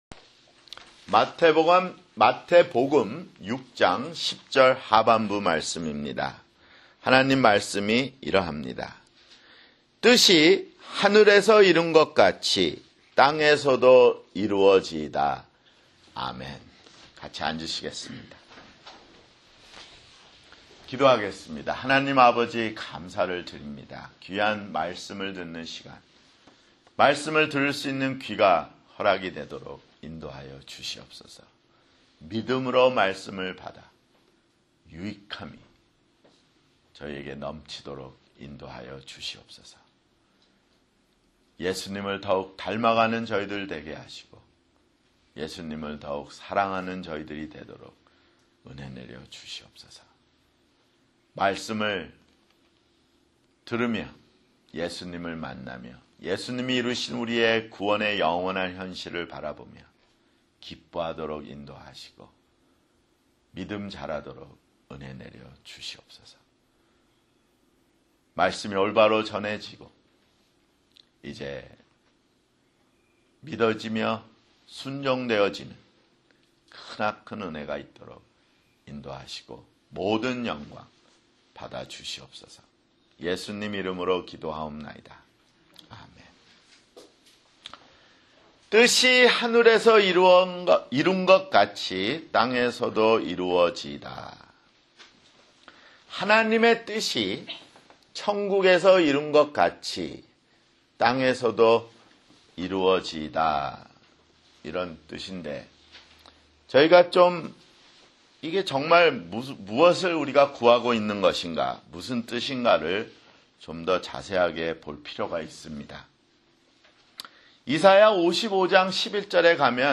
[주일설교] 주기도문 (4)